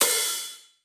Closed Hats
Medicated OHat 6.wav